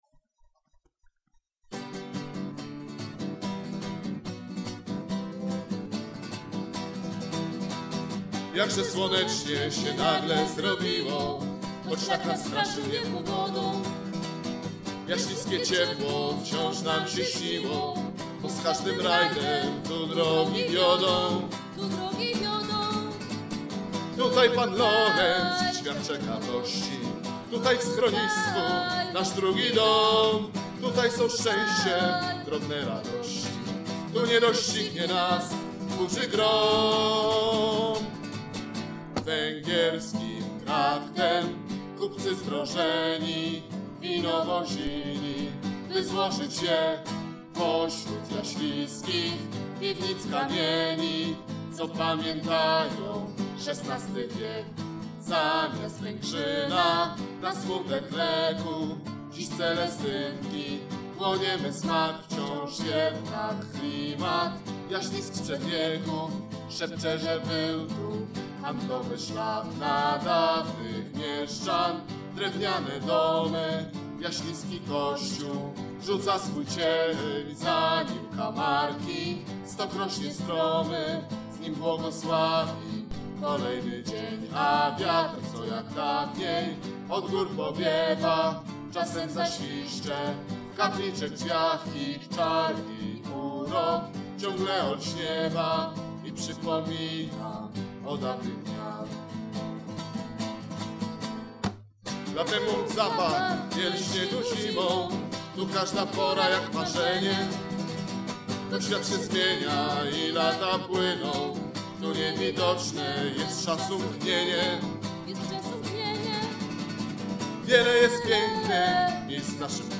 Nagrania dokonaliśmy w sierpniu 2004 bardzo domowym sposobem. Efektem jest 20 plików mp3 nieco szumiacych i dosyć cichych, ale za to naszych własnych.